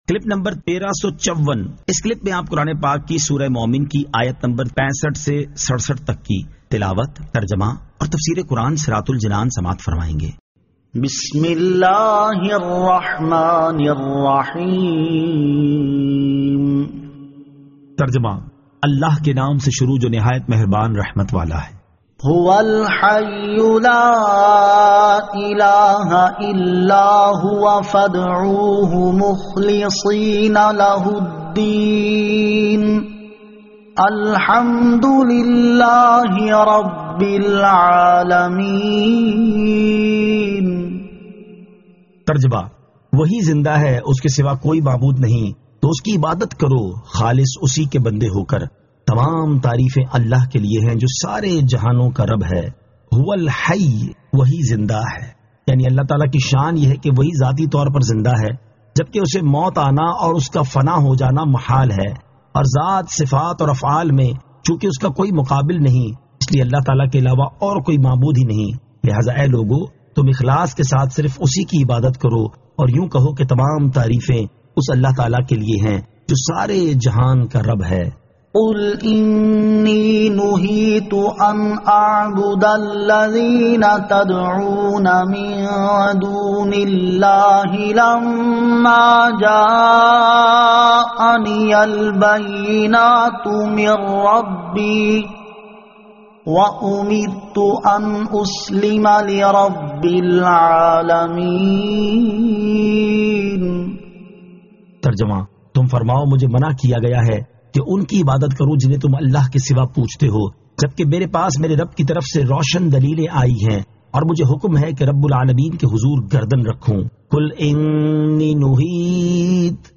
Surah Al-Mu'min 65 To 67 Tilawat , Tarjama , Tafseer